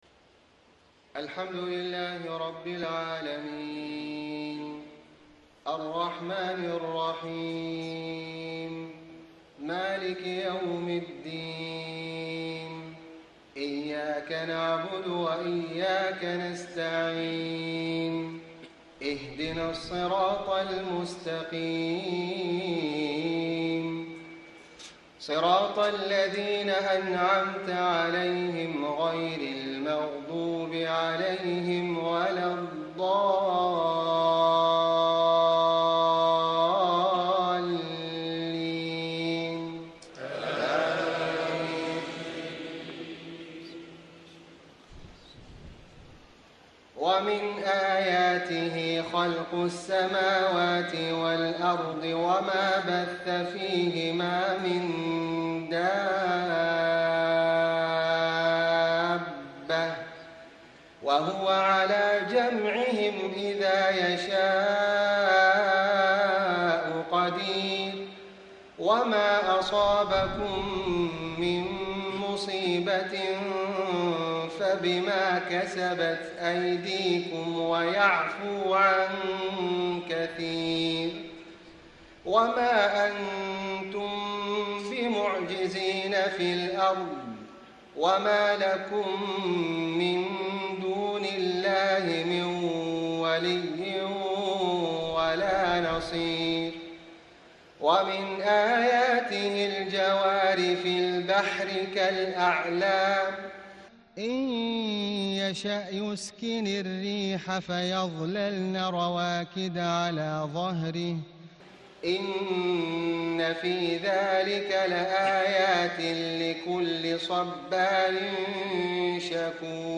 صلاة العشاء ٢ شعبان ١٤٣٥ من سورة الشورى > 1435 🕋 > الفروض - تلاوات الحرمين